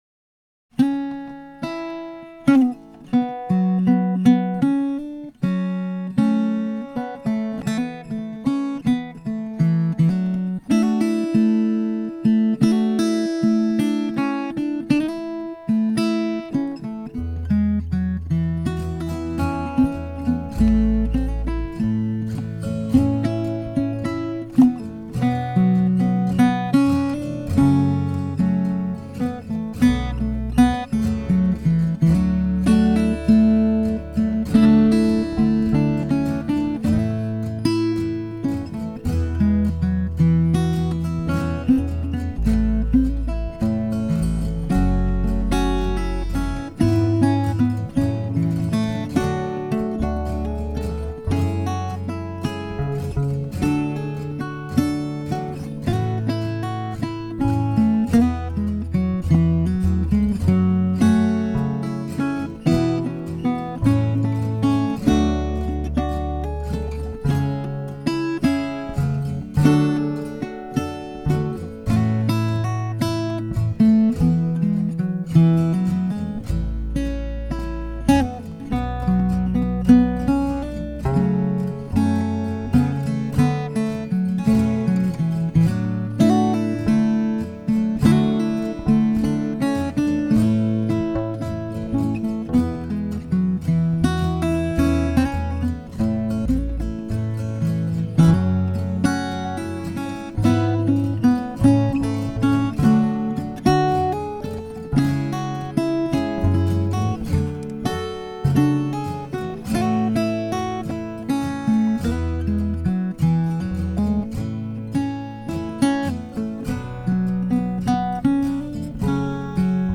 Instrumentals for Guitar